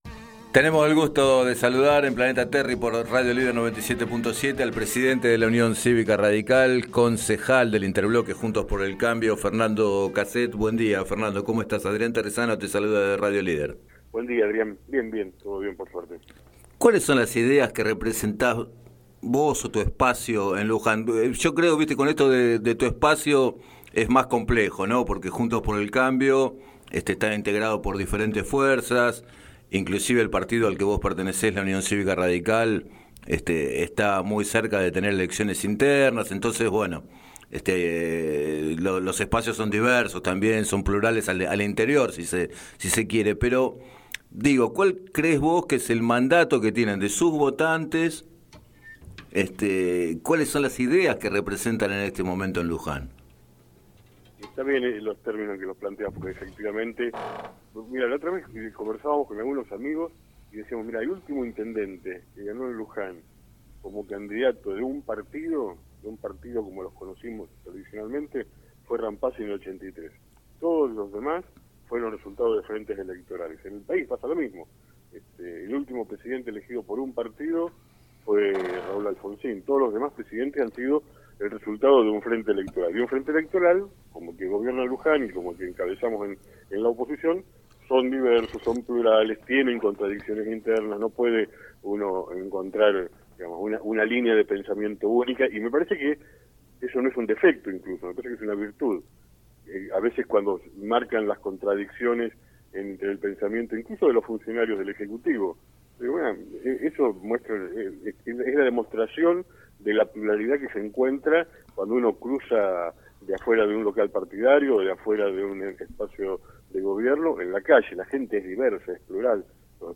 En declaraciones a Radio Líder 97.7, el edil reclamó poder acceder al expediente de la contratación de Usina Eco y a una rendición de cuentas sobre el uso de fondos desafectados por el Concejo, aunque los funcionarios estén ocupados.